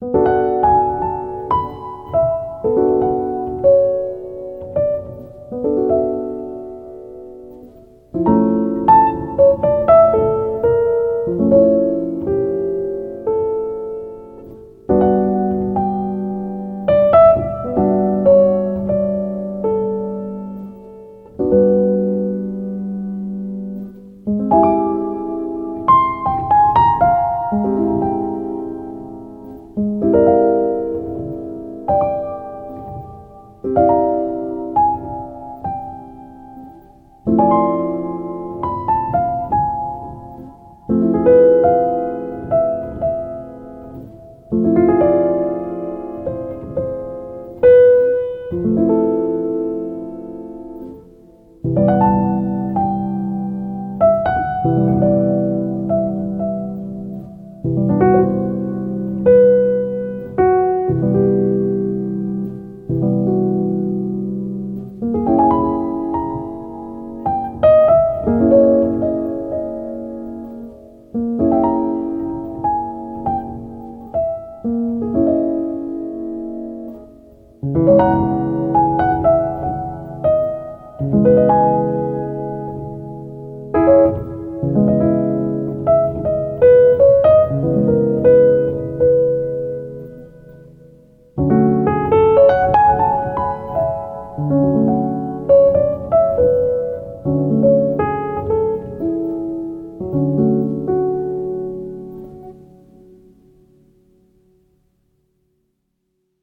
jazz improvisation june 1,2021
jazz_improvisation_0.mp3